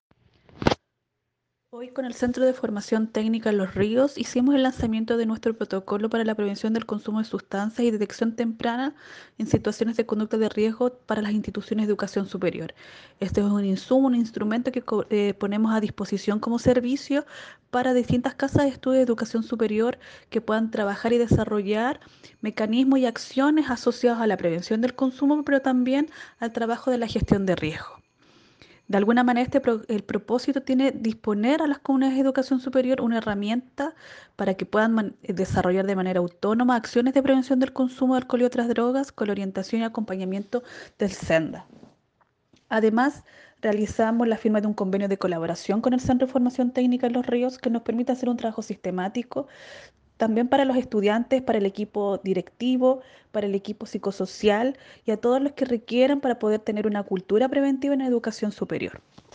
Cuña-DR-SENDA-Los-Ríos-Cinthia-Lara.mp3